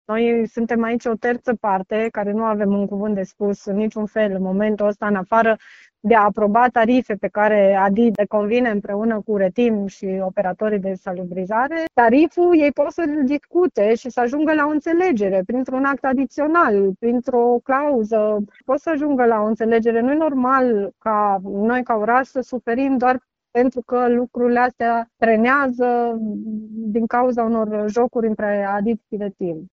Viceprimarul Paula Romocean spune că este responsabilitatea ADID să impună tarifele stabilite.